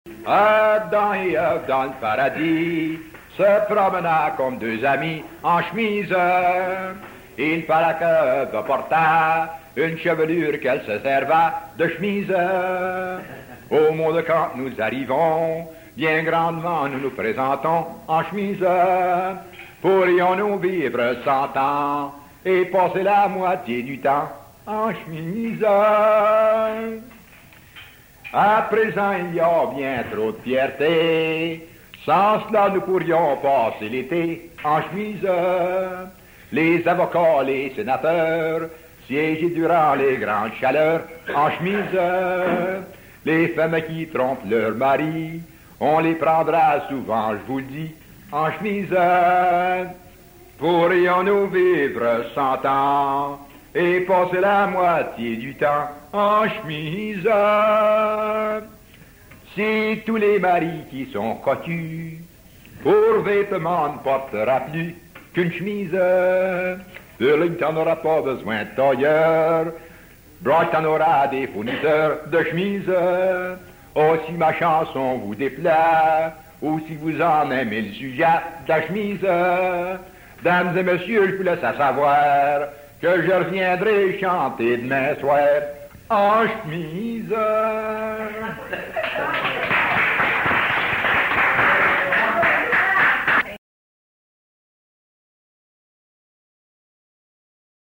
Folk Songs, French--New England Folk Songs, French--Québec (Province)